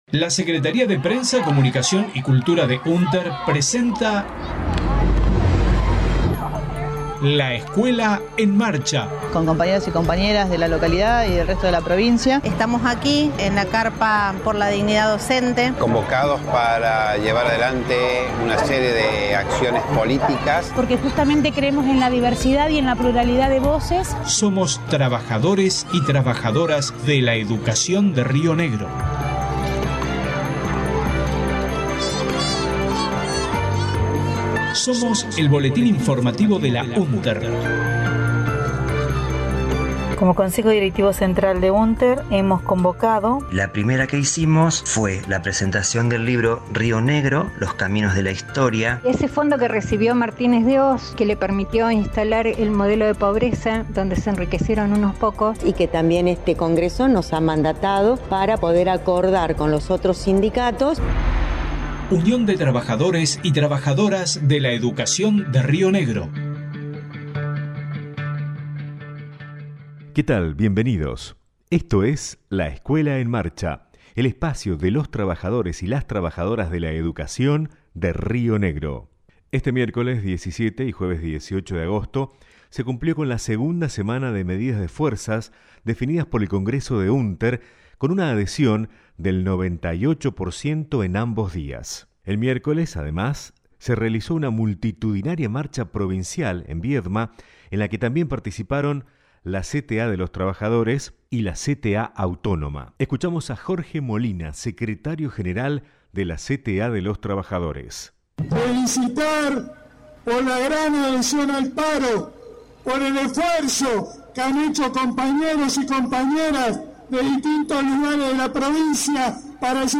audios acto de cierre de marcha provincial en Viedma el 17/08/22